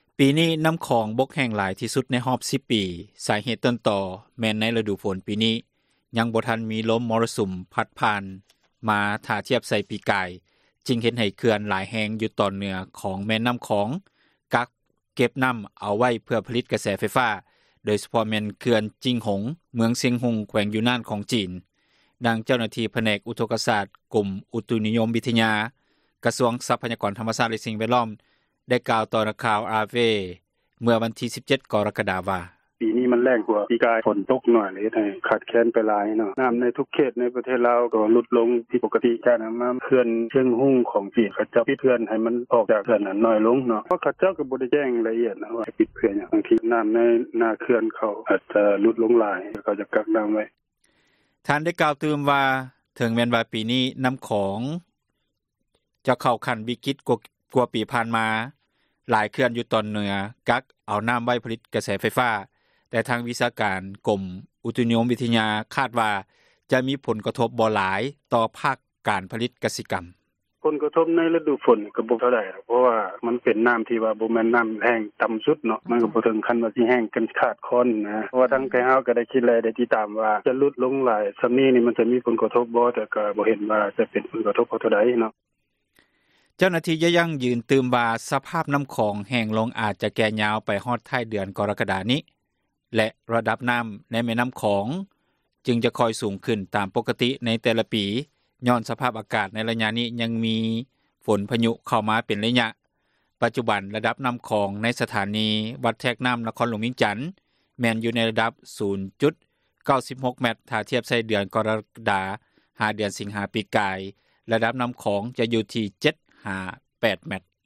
ນໍ້າຂອງບົກແຫ້ງຕໍ່າສຸດ ໃນຮອບ 10 ປີ – ຂ່າວລາວ ວິທຍຸເອເຊັຽເສຣີ ພາສາລາວ